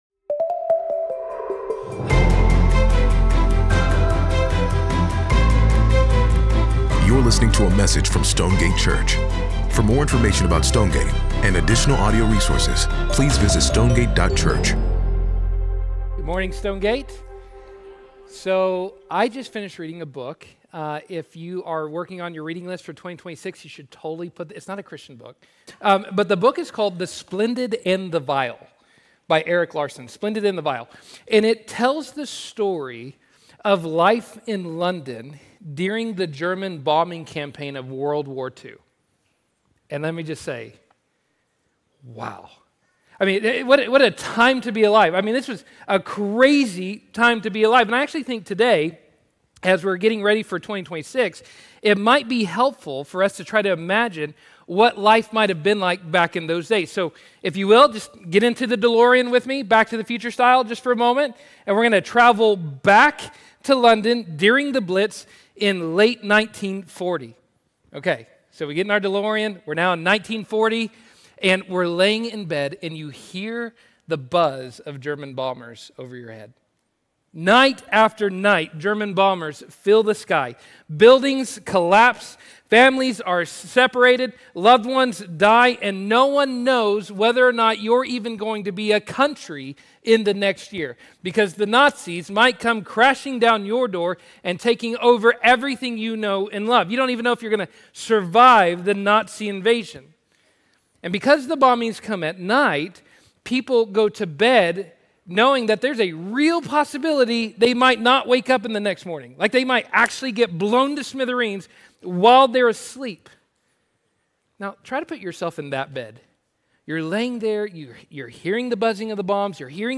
Sermon pod 12.28.mp3